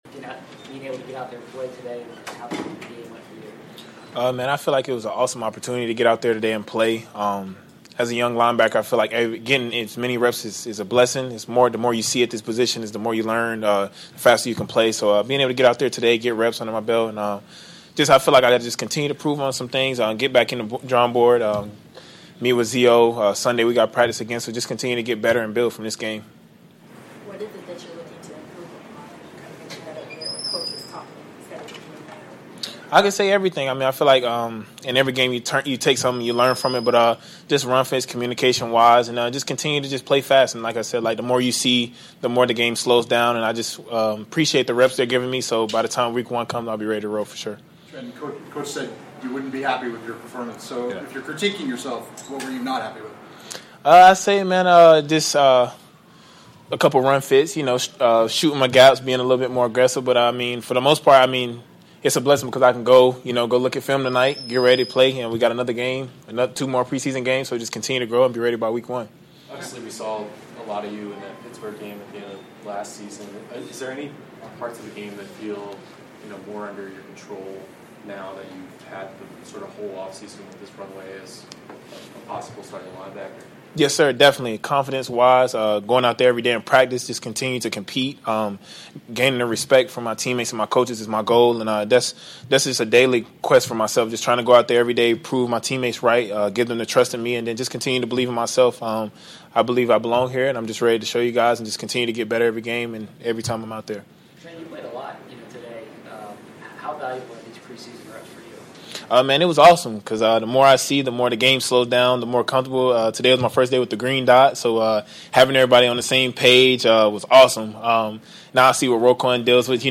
Locker Room Sound